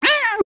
Divergent / mods / Soundscape Overhaul / gamedata / sounds / monsters / cat / 10.ogg